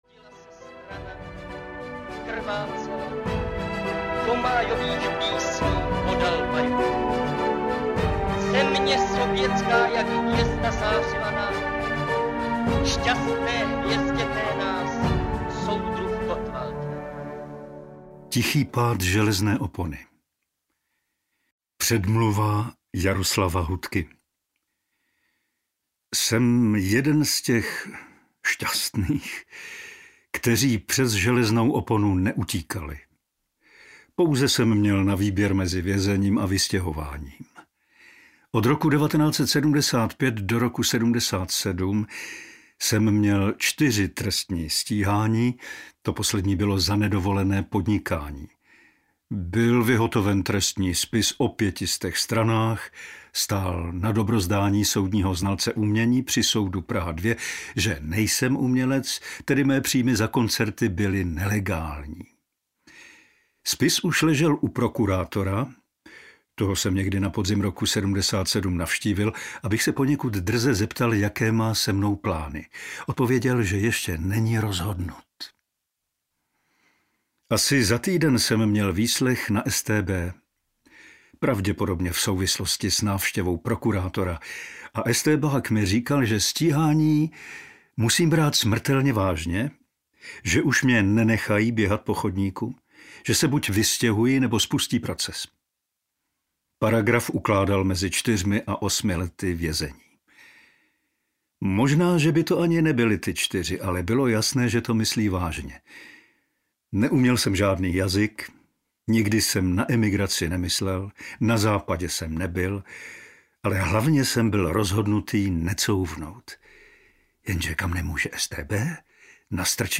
Příběhy železné opony audiokniha
Ukázka z knihy
pribehy-zelezne-opony-audiokniha